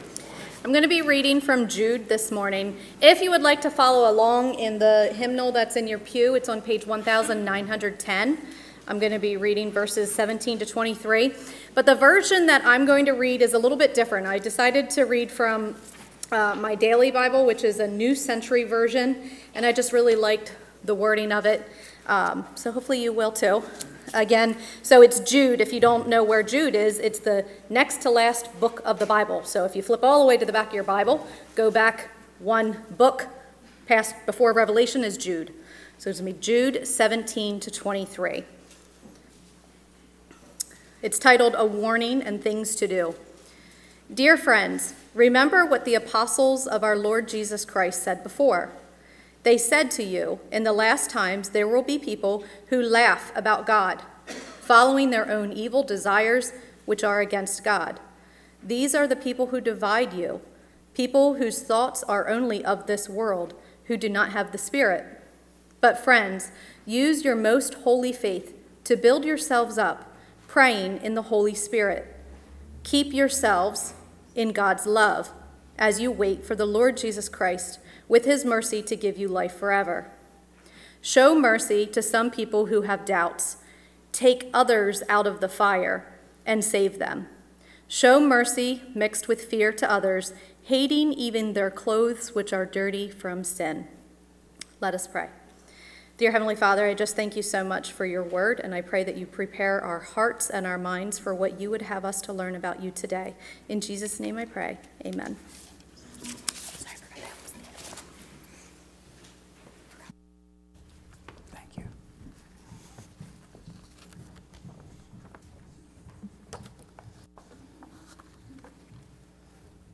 A message from the series "October 2025."